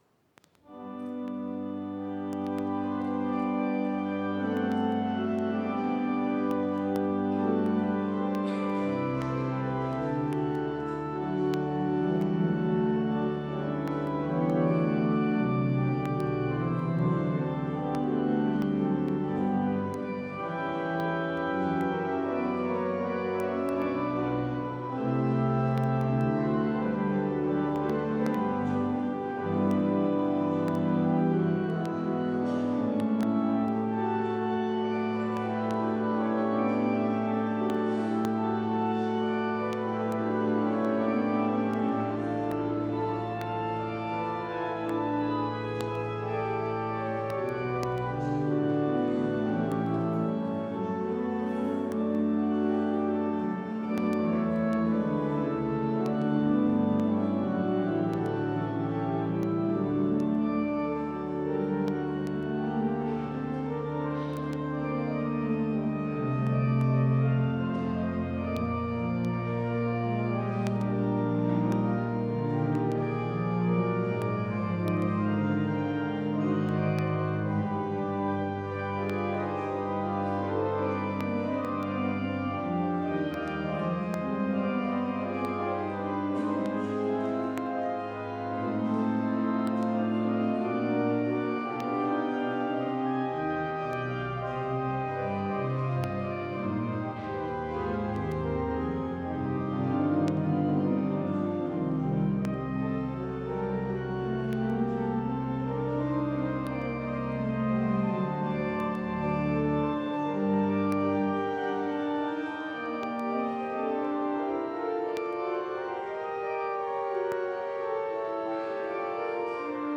Orgelstück zum Ausgang
Audiomitschnitt unseres Gottesdienstes vom Kirchweihfest 2024